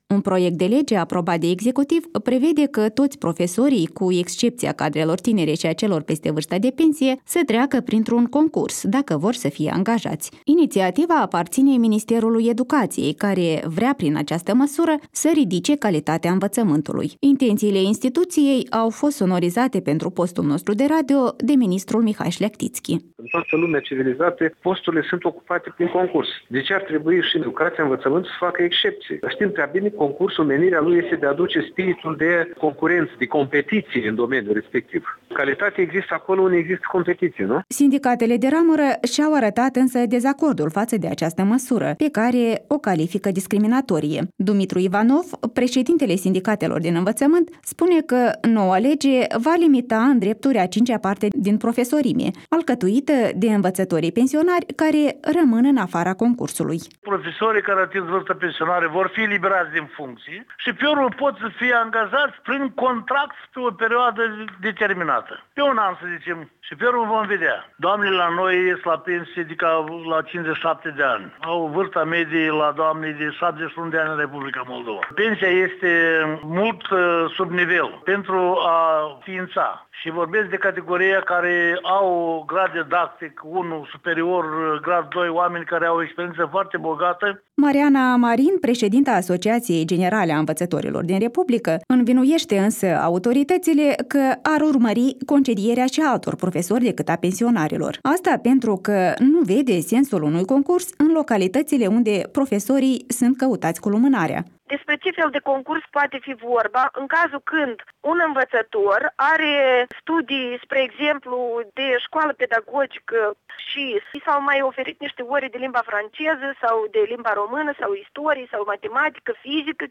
Intenţiile instituţiei au fost detaliate pentru postul nostru de radio de ministrul Mihai Şleahtiţchi: „În toată lumea civilizată posturile sînt ocupate prin concurs.